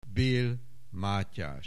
Bél, Mátyás (1684-1749) Aussprache Aussprache